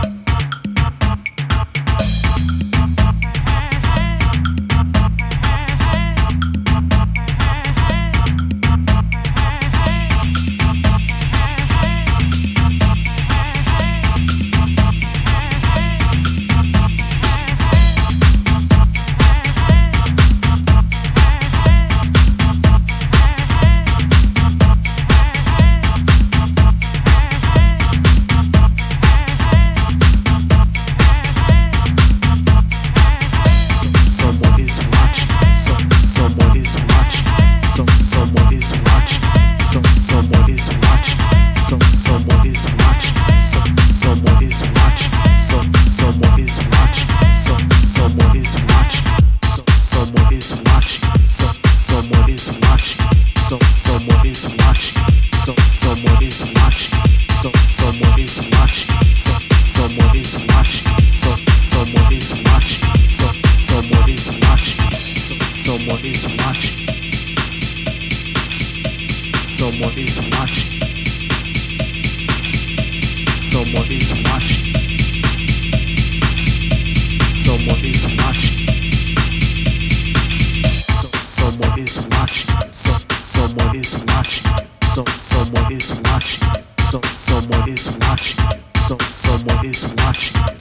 etc. It is a fat house tune with excellent stability